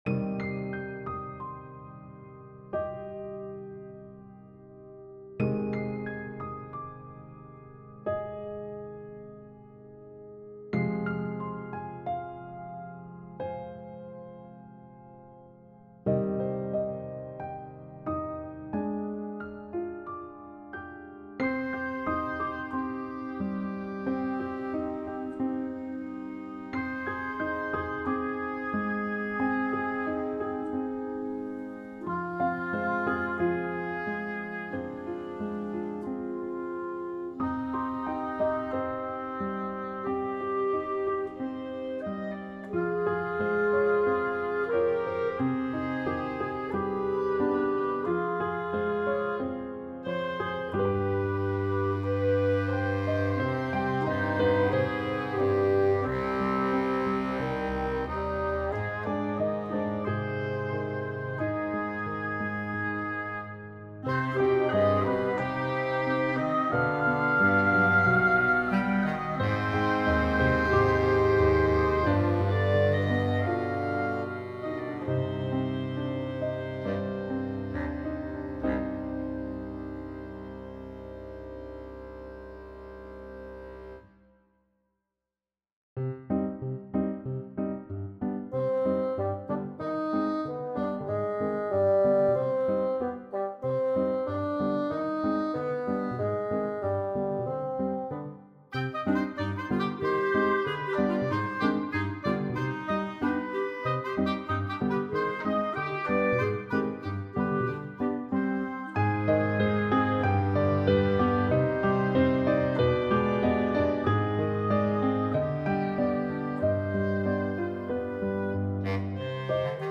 Really just shot for the Halloween/Spooky vibe with both the Instrumentation, key, and rhythmic inflections.
I have also had a change of heart on the timbre of the score being in C minor, and have transposed it to B minor, one half step lower for a slightly more rich tonality.